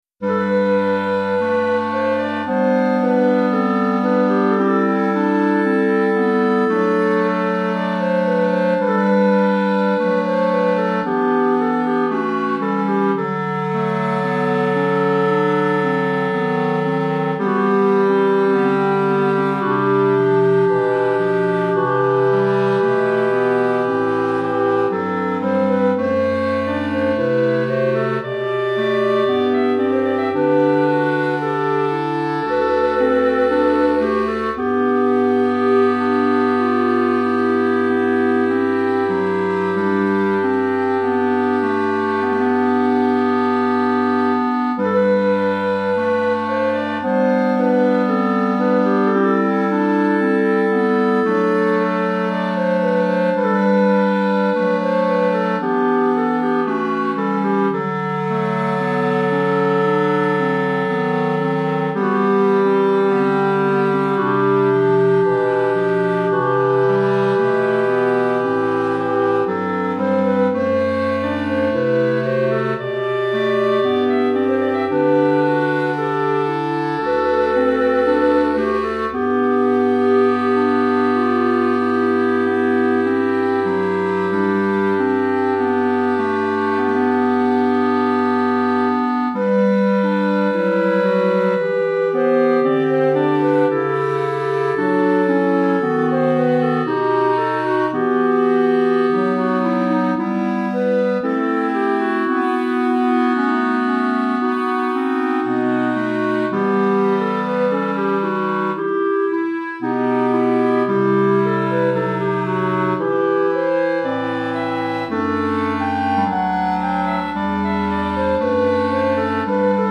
3 Clarinettes en Sib et Clarinette Basse